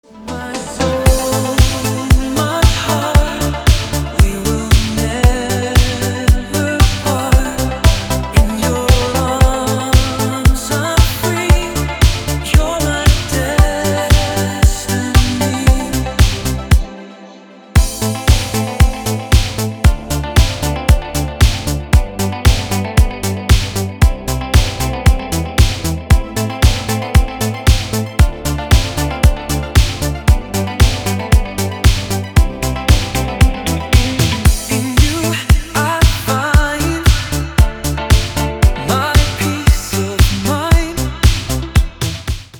Рингтон в стиле 80-х